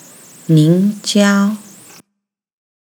ニン ジャオ
níng jiāo